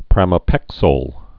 (prămə-pĕksōl)